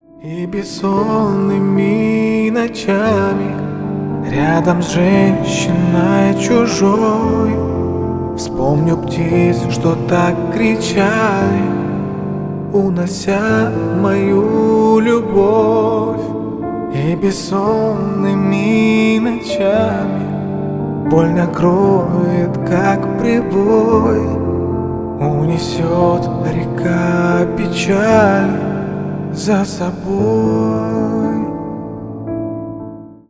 Поп Музыка
грустные # кавер